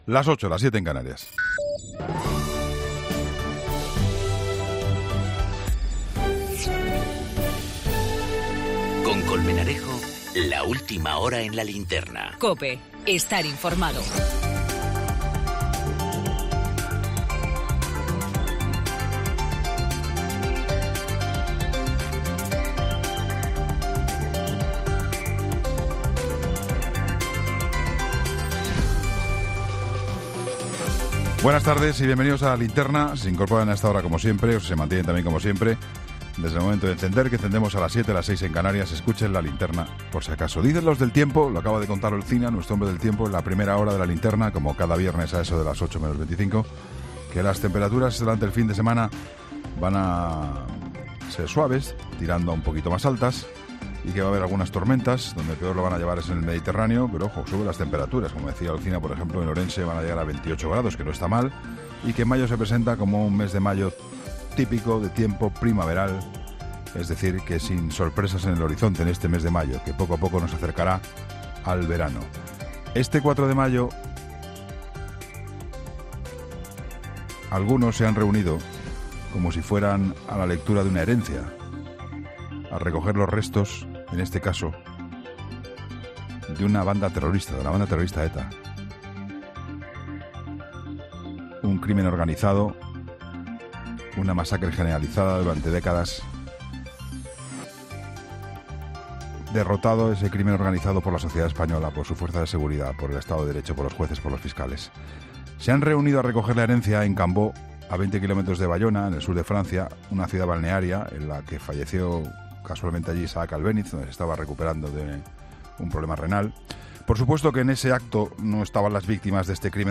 Juan Pablo Colmenarejo, director de ‘La Linterna’, ha centrado su comentario de este viernes en el acto de propaganda de ETA al sur de Francia para escenificar su disolución